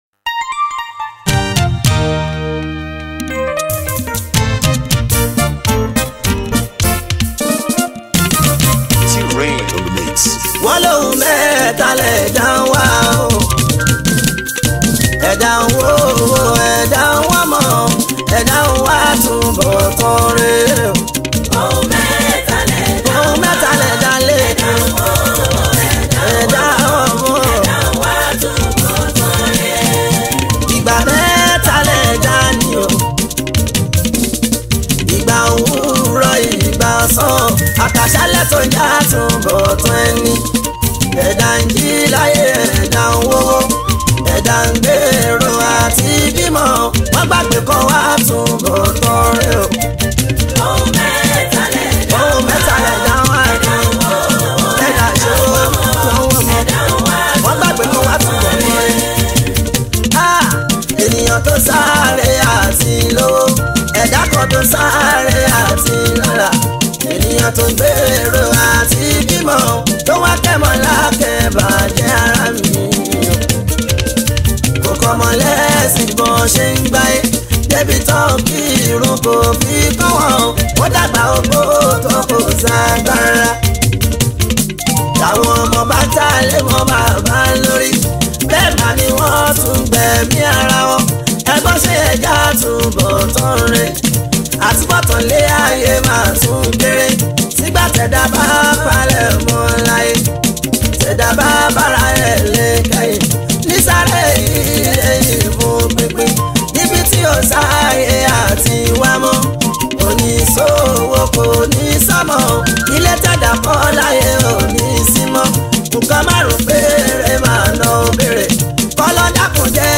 Fuji Music